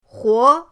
huo2.mp3